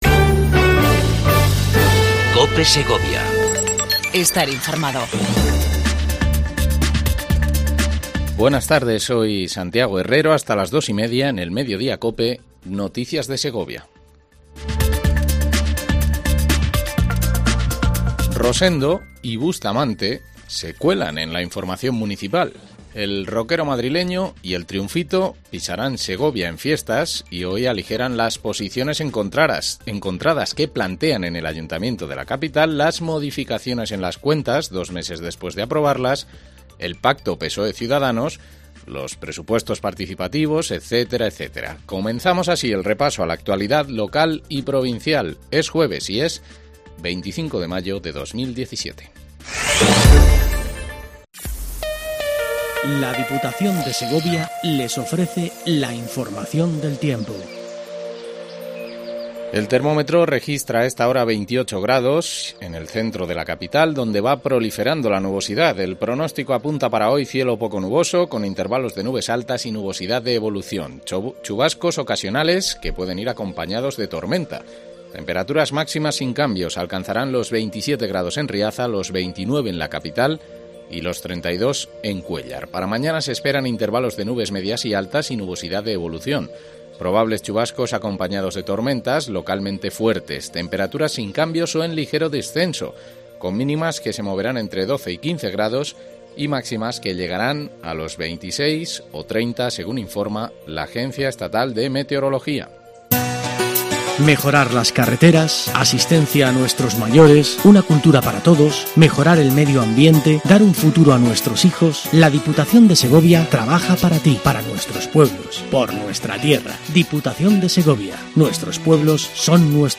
INFORMATIVO MEDIODIA COPE EN SEGOVIA 25 05 17